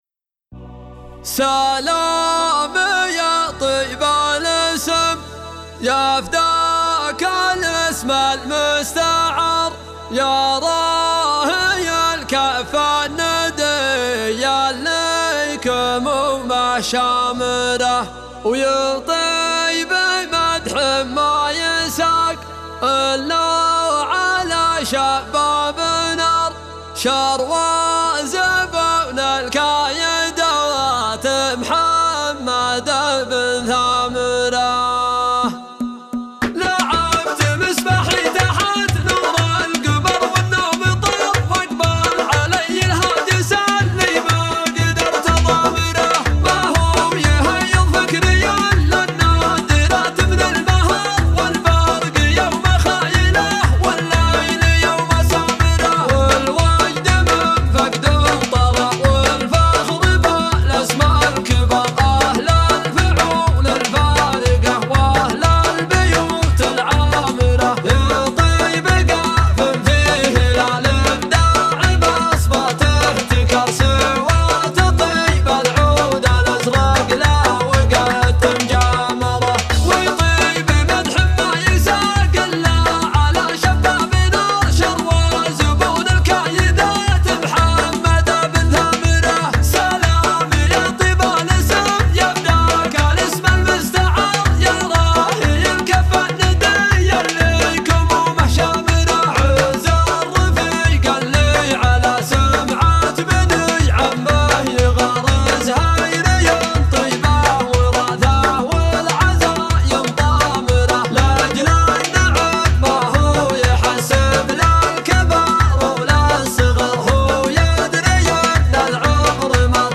الزهيري